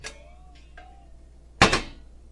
烹饪 " 把水壶
Tag: 厨房 推杆 水壶 桌子